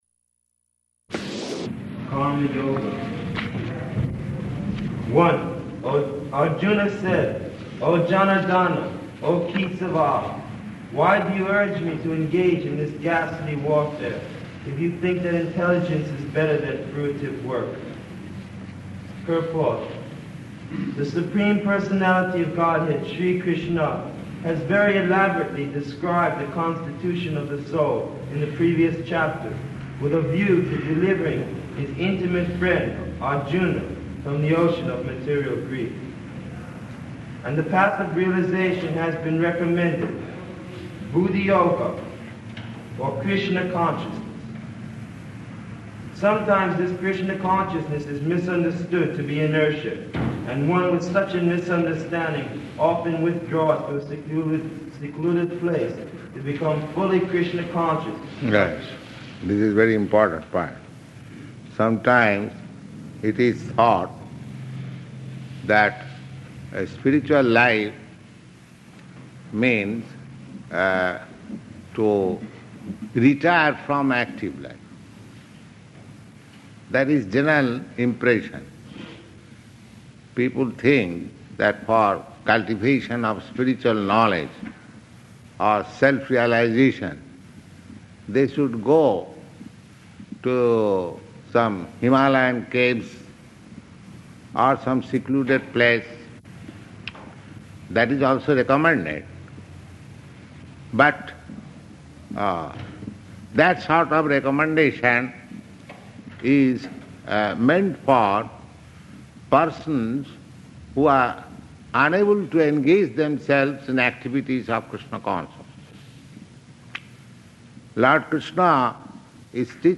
-- Type: Bhagavad-gita Dated: December 20th 1968 Location: Los Angeles Audio file